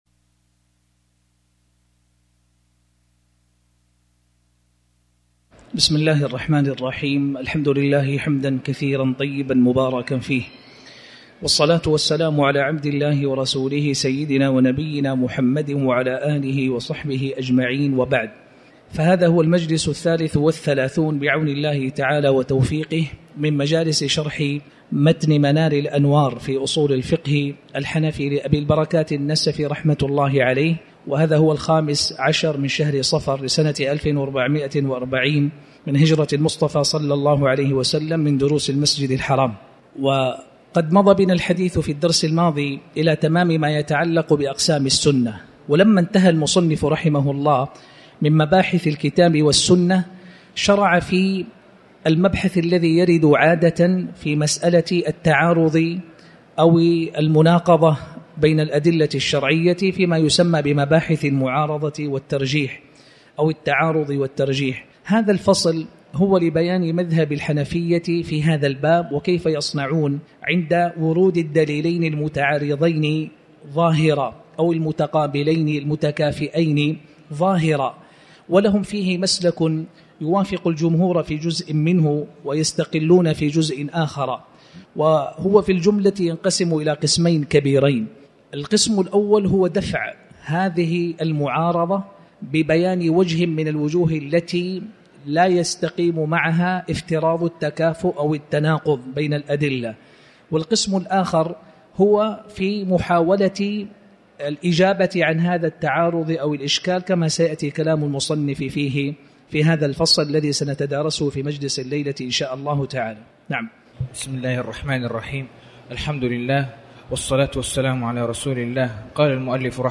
تاريخ النشر ١٥ صفر ١٤٤٠ هـ المكان: المسجد الحرام الشيخ